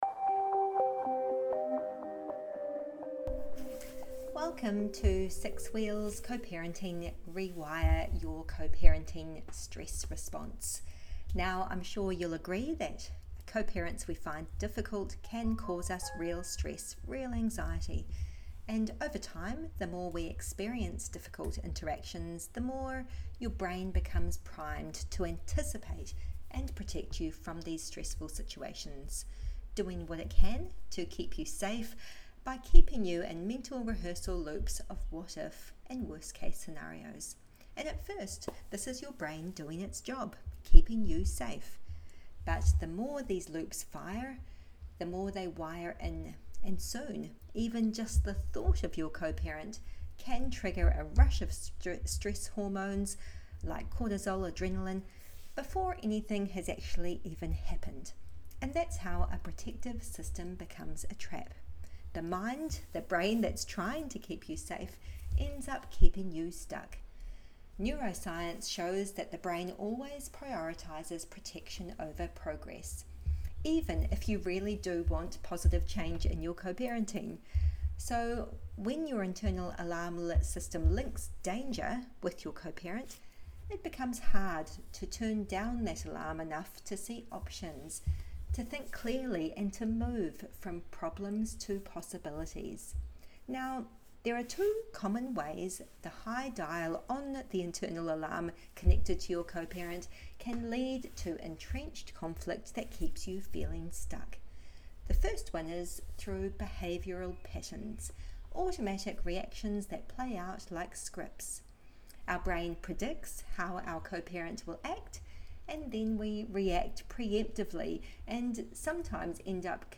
20-Minute Guided Hypnosis